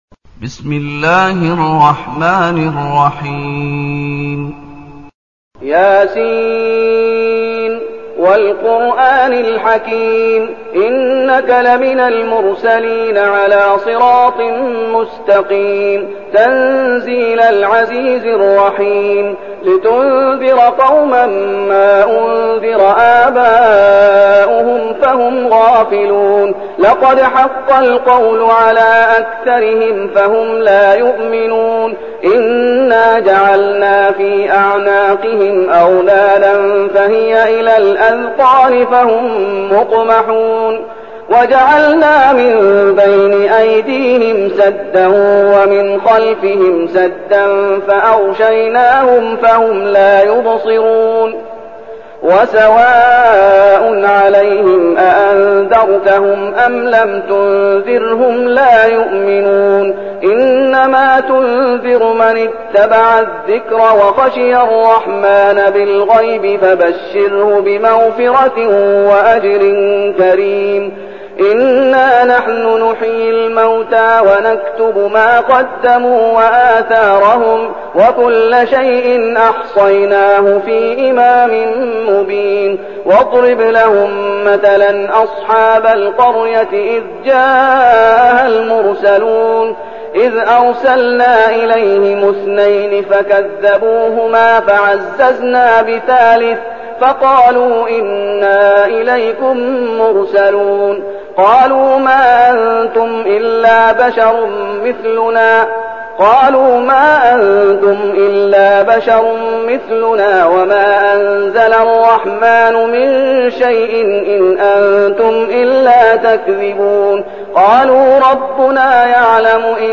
تلاوة سورة يس بصوت الشيخ محمد أيوب
تاريخ النشر ١ محرم ١٤١٠ المكان: المسجد النبوي الشيخ: فضيلة الشيخ محمد أيوب فضيلة الشيخ محمد أيوب سورة يس